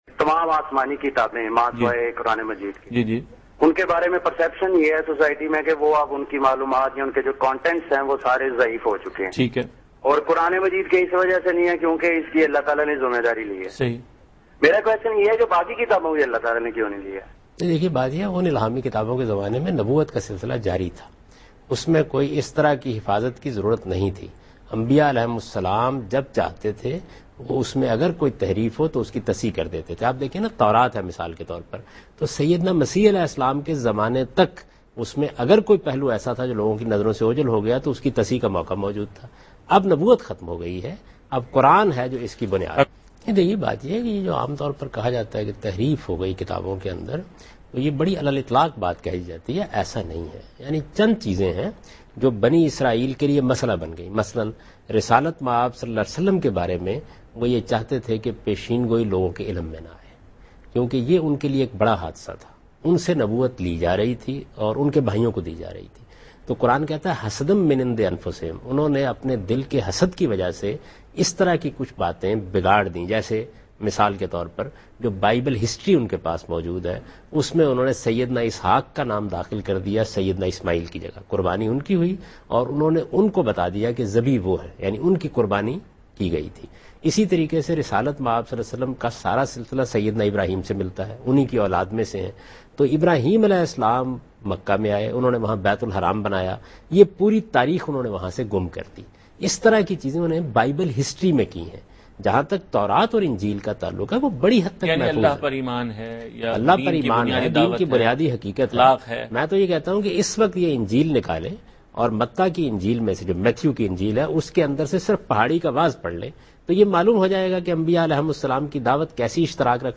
Category: TV Programs / Dunya News / Deen-o-Daanish /
Javed Ahmdad Ghamidi answers a question about "Guarantee of Safety of Divine Books" in program Deen o Daanish on Dunya News.
جاوید احمد غامدی دنیانیوز کے پروگرام دین و دانش میں آسمانی کتابوںکی حفاظت سے متعلق ایک سوال کا جواب دے رہے ہیں۔